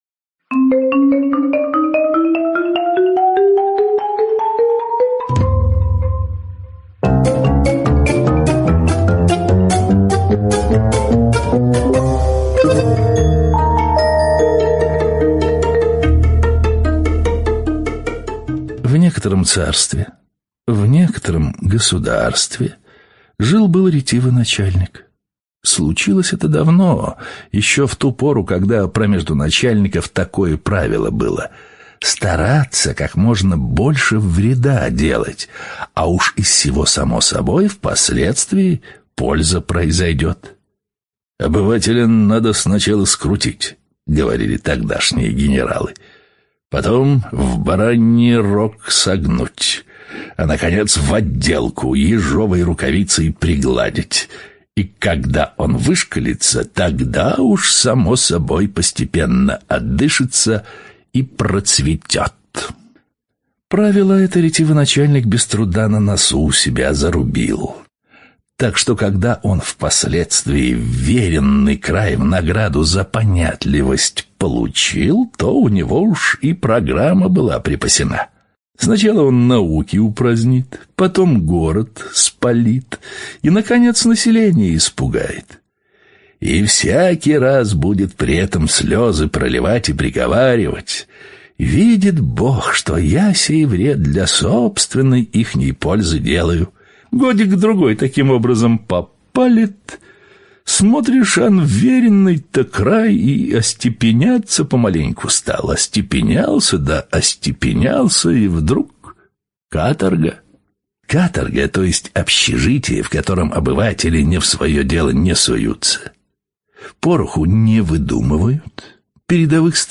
Сказка о ретивом начальнике — слушать аудиосказку Михаил Салтыков-Щедрин бесплатно онлайн